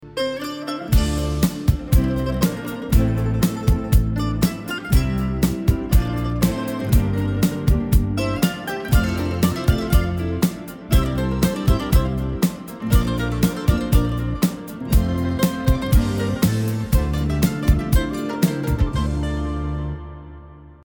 آهنگ زنگ